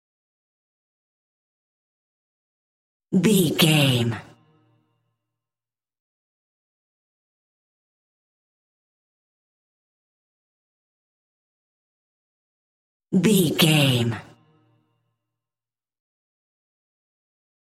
Ionian/Major
techno
trance
synthesizer
synthwave
instrumentals